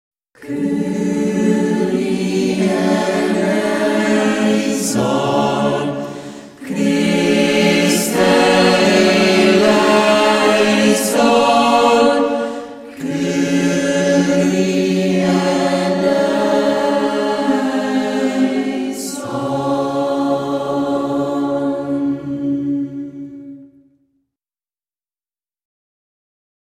Wir sind eine Gruppe junger Leute aus Wolfakirchen und Umgebung, die mit Singen charismatischer Lieder Nächstenliebe verkünden und Freude am Singen zum Lob Gott